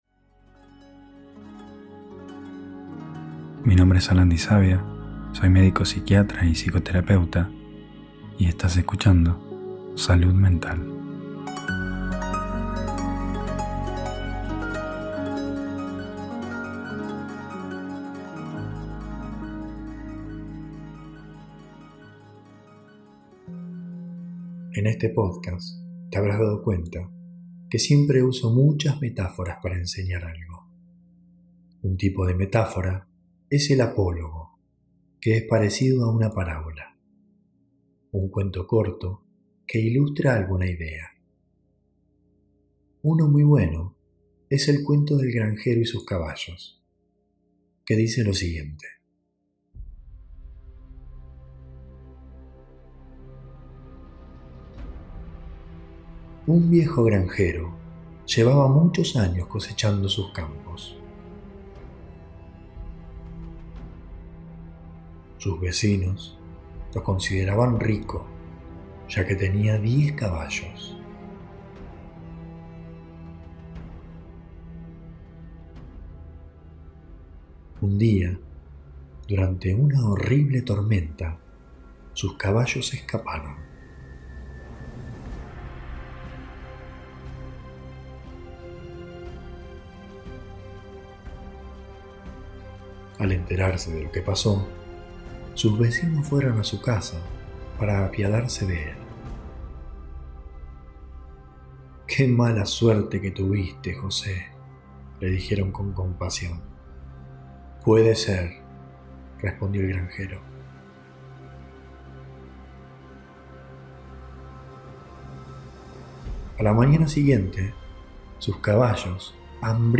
Extracto del taller.